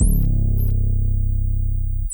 Frequency 808.wav